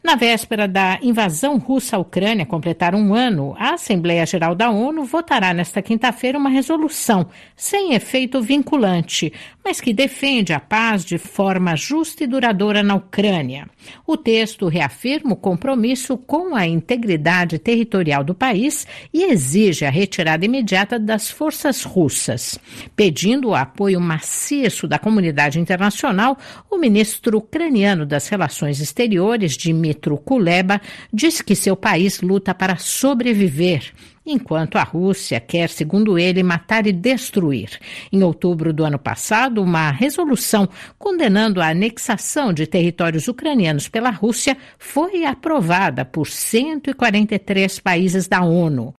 Paris